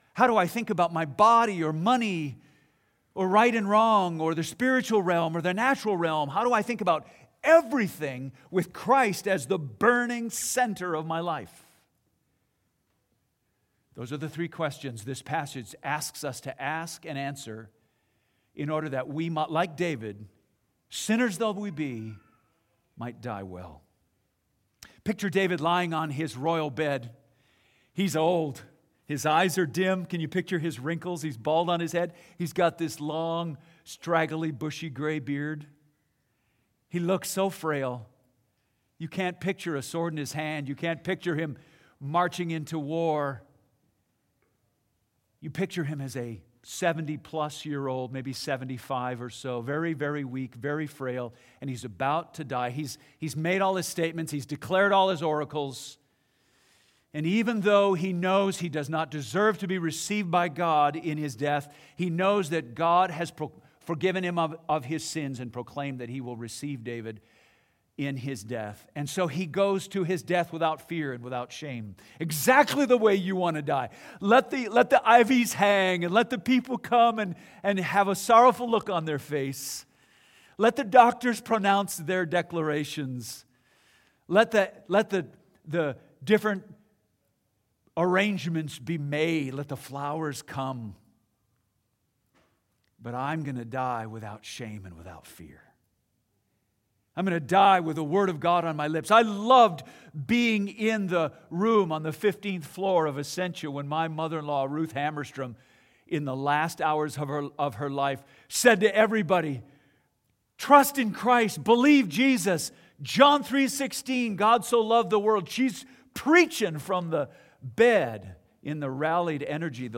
Sermons | The Landing Church